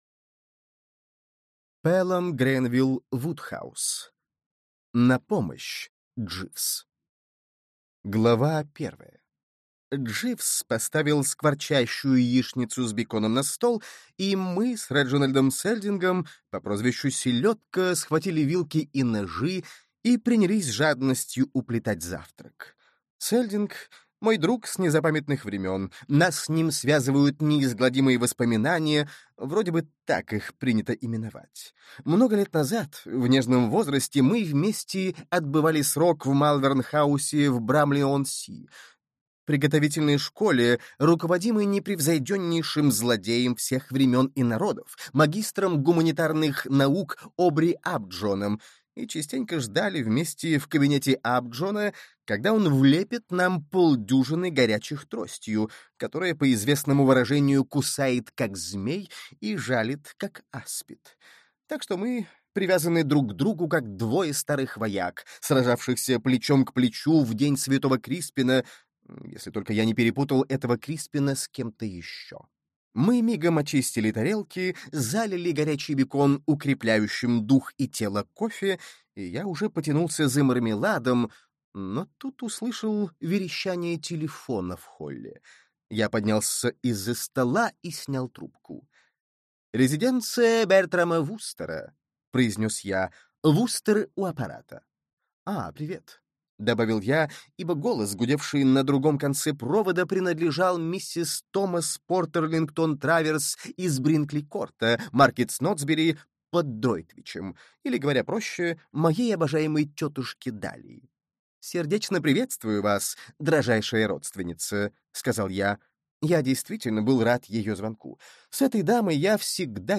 Аудиокнига На помощь, Дживс!